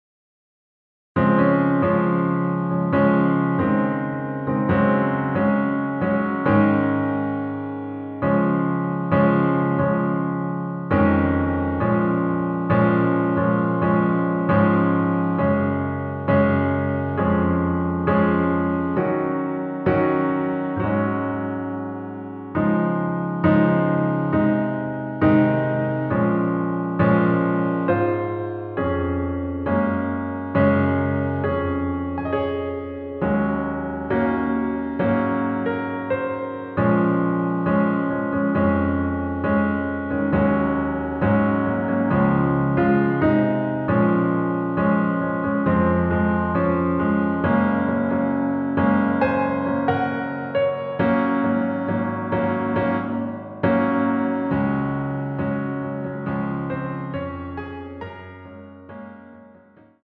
반주를 피아노 하나로 편곡하여 제작하였습니다.
원키(Piano Ver.) MR입니다.